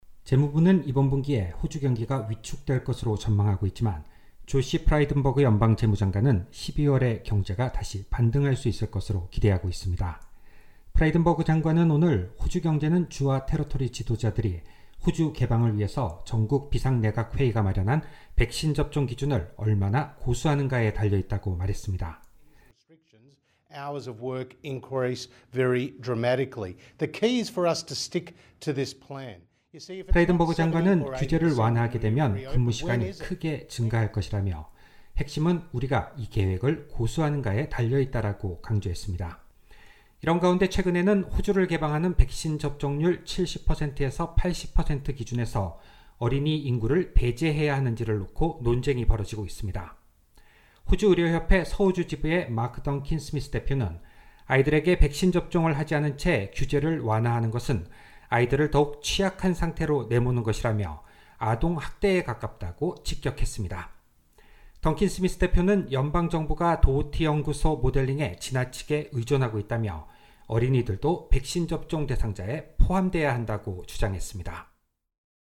2508_morning_audio_news.mp3